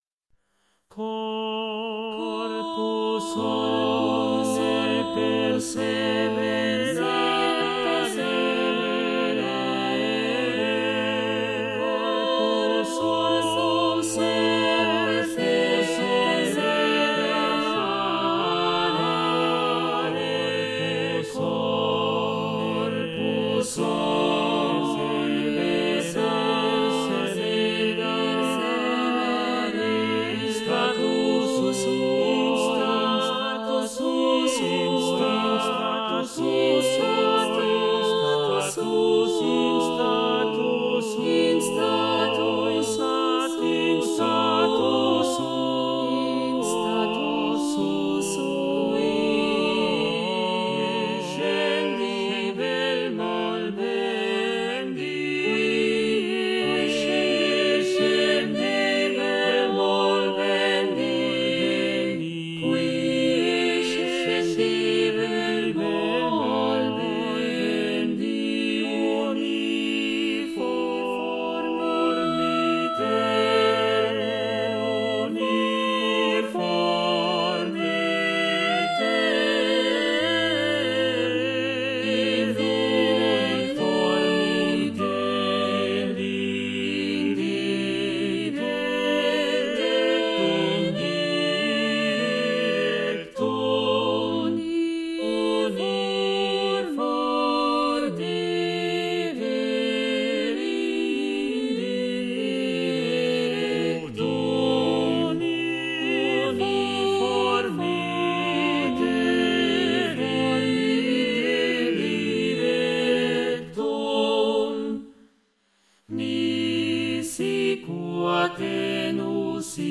Renaissance motet for 5 voices over Newton's first law of physics in latin